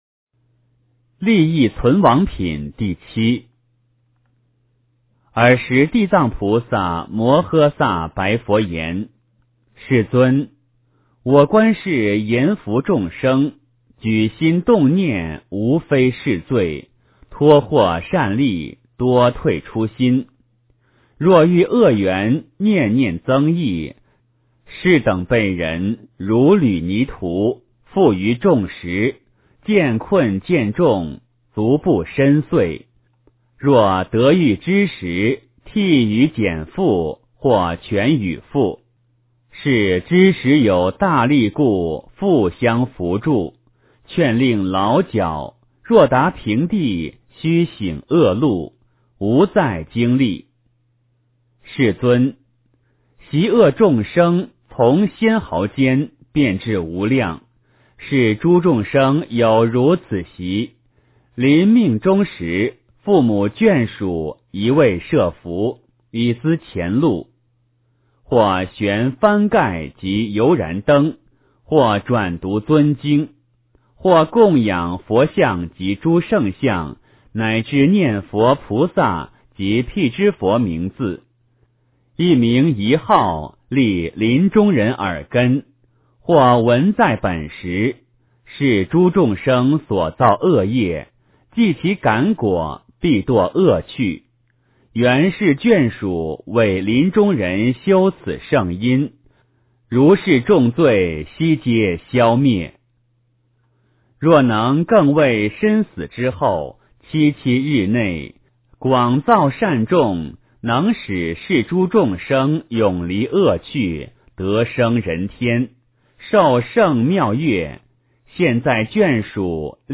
地藏经-利益存亡品第七 诵经 地藏经-利益存亡品第七--佛经 点我： 标签: 佛音 诵经 佛教音乐 返回列表 上一篇： 佛说阿弥陀经上 下一篇： 地藏经-较量布施功德缘品第十 相关文章 给你祝福--梵净天籁组合 给你祝福--梵净天籁组合...